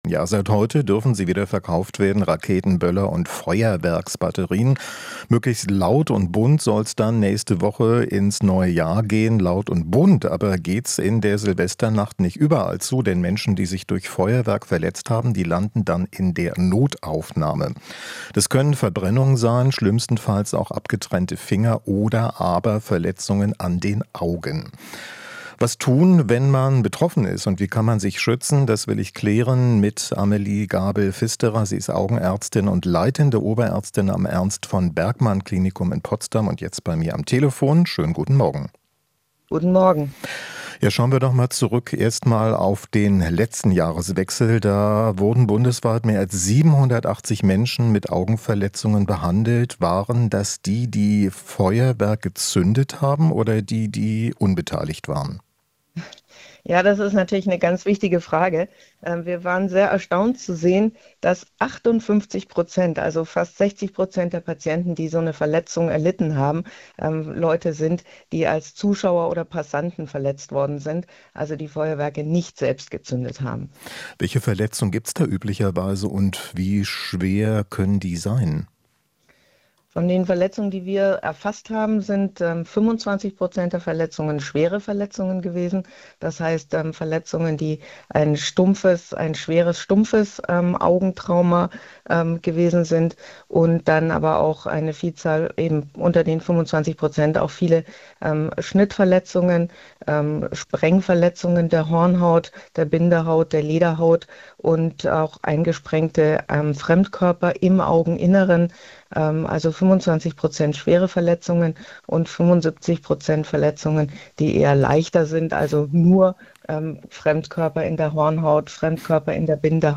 Interview - Ärztin: Kein Rückgang bei Verletzten durch Böller zu erwarten